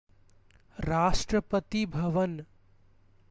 Rashtrapati_bhavan_pronunciation.ogg.mp3